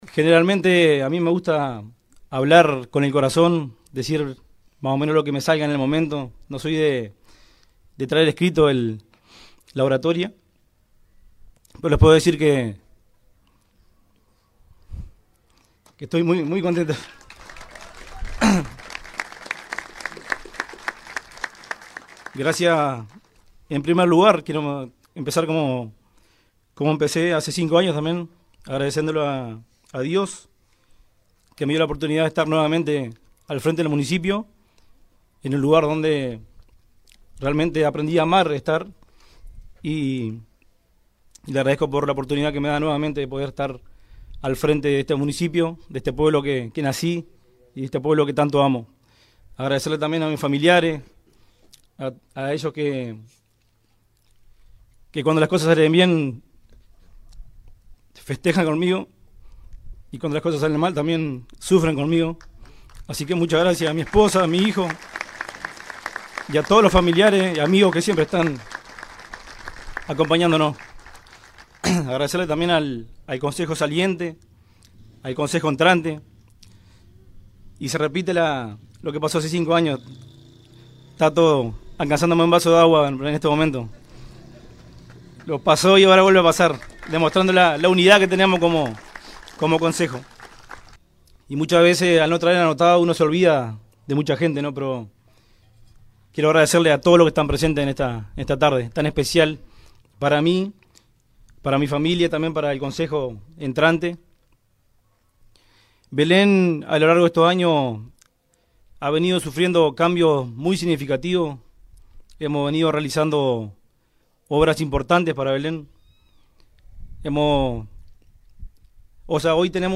La ceremonia se desarrolló ante un importante marco de público y contó con la presencia de autoridades locales, departamentales y nacionales.
Por su parte, el alcalde reelecto, Luis Enrique Zuliani, visiblemente emocionado, agradeció el respaldo del pueblo de Belén y recordó sus comienzos al frente del Municipio.